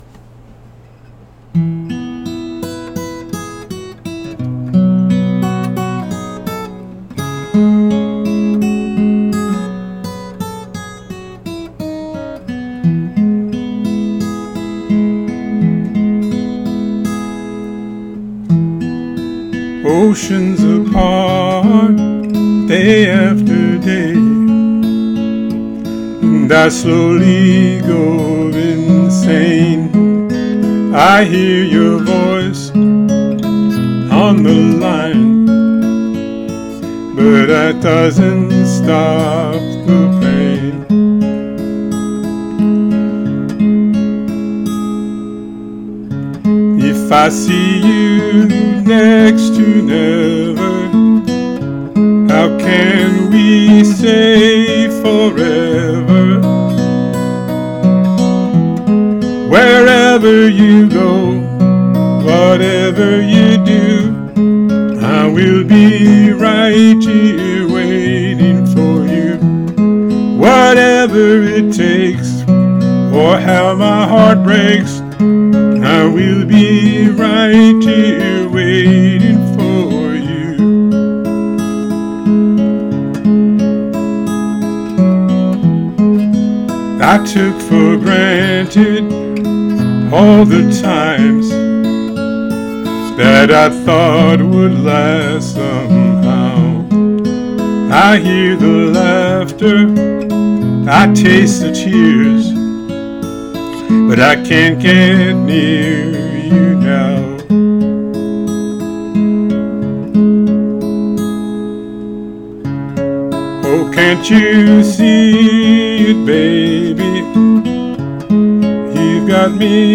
I’ve composed a guitar background. Here’s my cover.